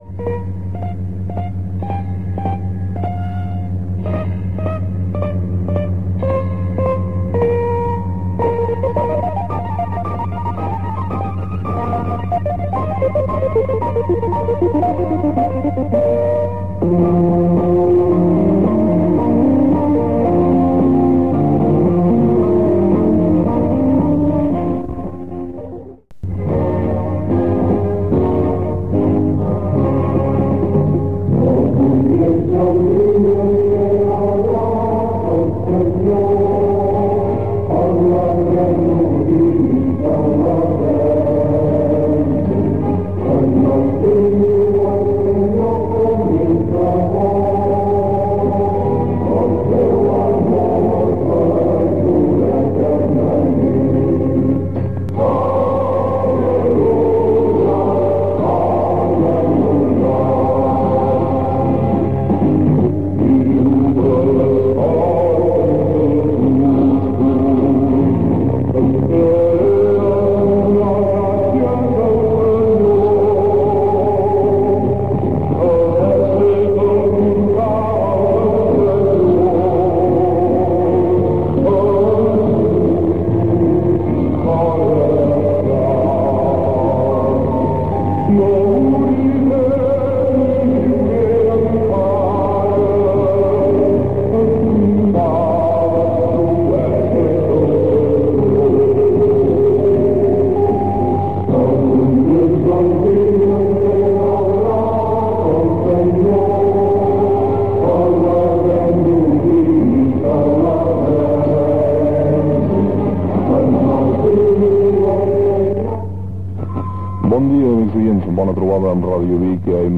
Inici d'emissió i programació musical